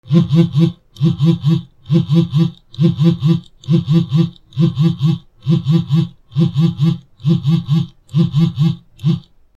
/ G｜音を出すもの / G-01 機器_電話
携帯電話 バイブ 机
ブブブ